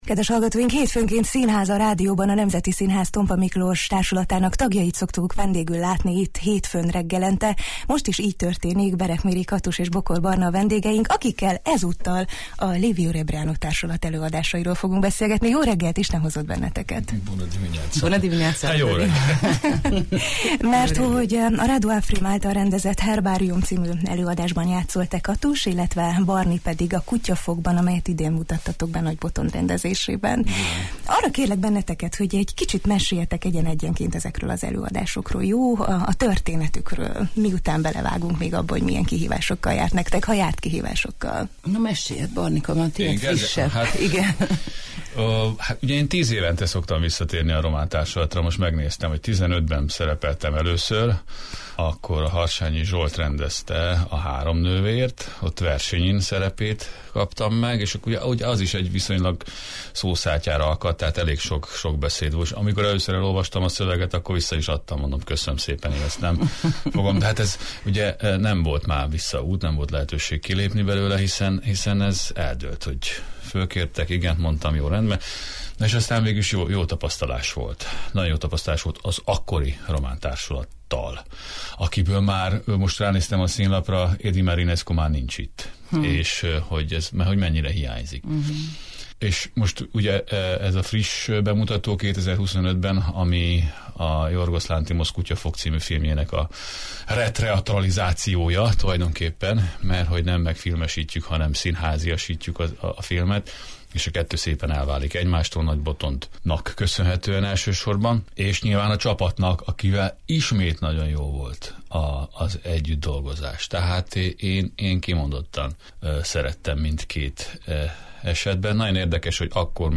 Románia nemzeti ünnepén különleges beszélgetéssel készültünk a Jó reggelt, Erdély! hallgatóinak.